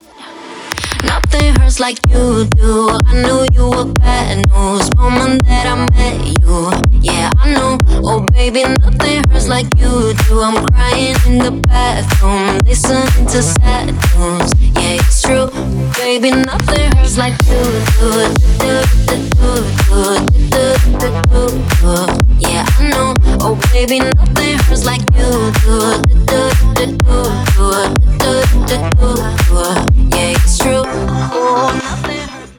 • Качество: 320, Stereo
мощные
Club House
качающие
красивый женский голос
клубняк